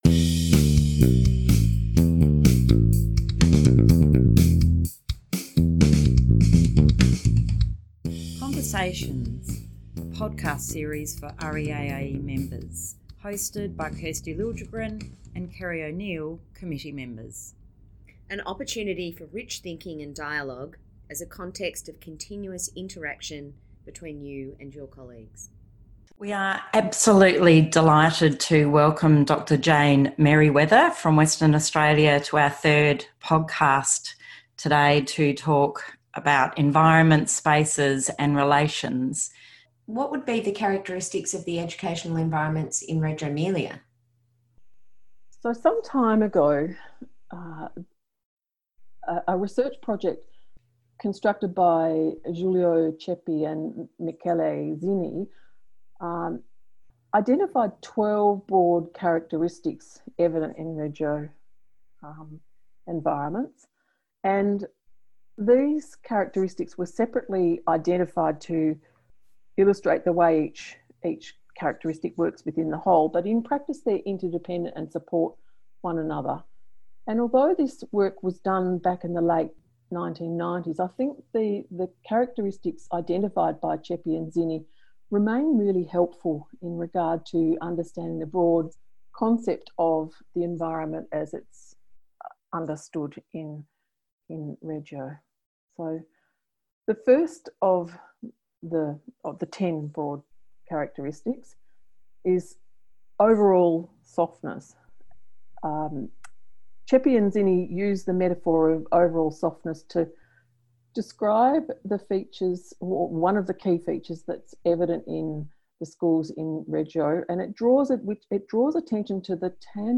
It is an opportunity for rich thinking and dialogue, as a context of continuous interaction, between you and your colleagues.